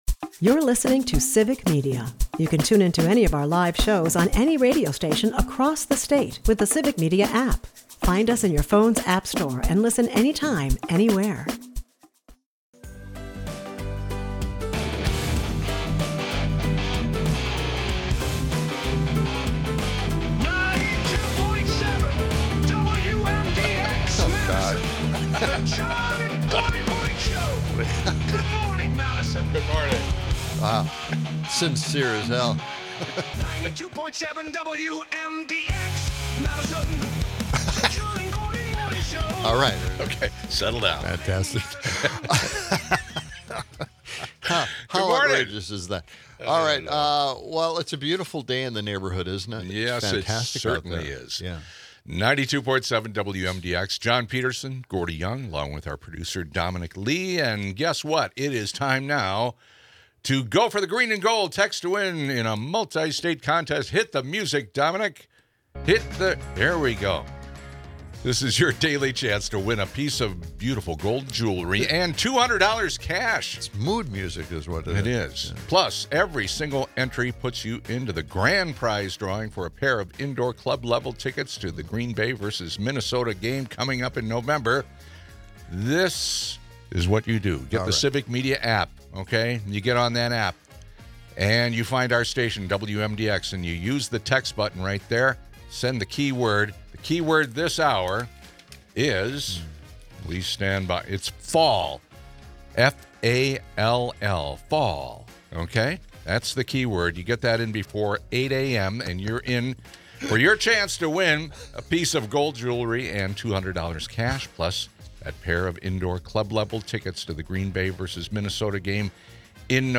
Weather updates, a contest reminder, and a spirited defense of factual education amidst political polarization round out the show, offering both heated debate and practical insights.